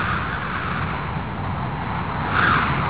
wind2.WAV